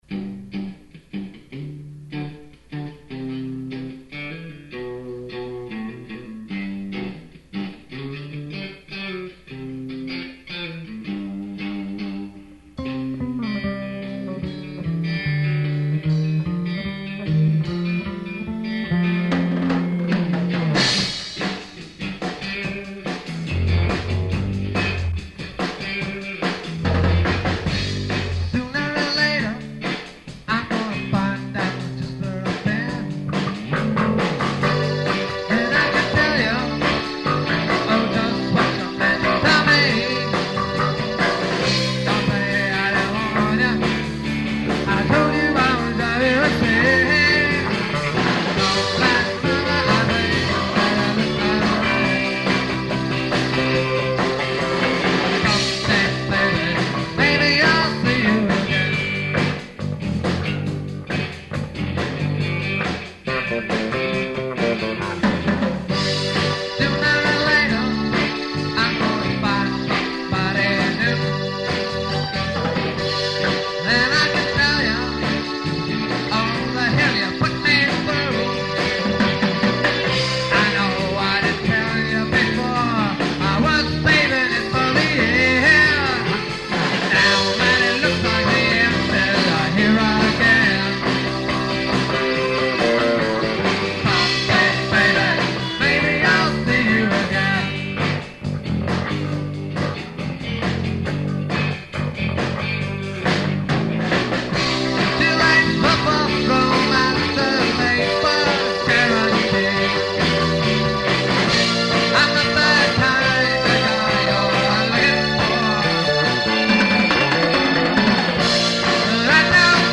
Keep in mind that these are from 20-year old cassette tapes, so sound quailty is definitely an issue. We apologize in advance for all the mistakes and questionable singing, but this was a practice session after all.
The band played some covers and mostly original material in the style of ska and reggae.